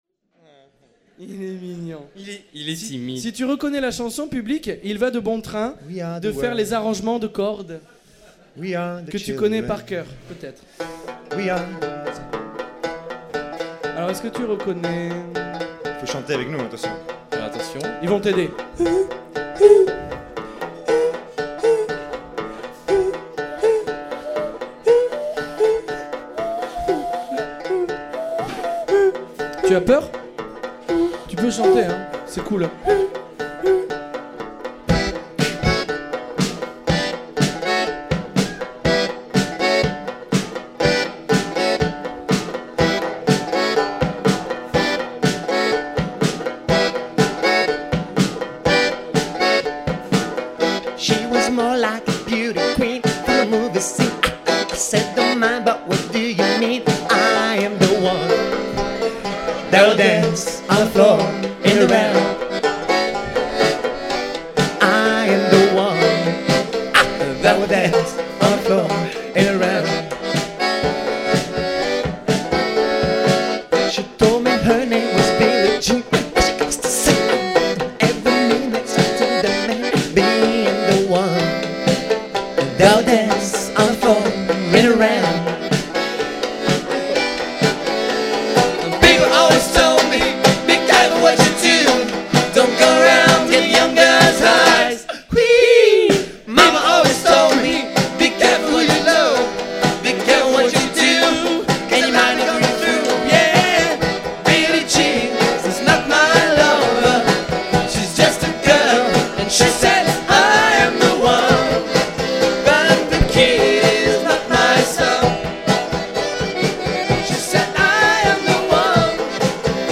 Live (07/05/05) en public avec des gen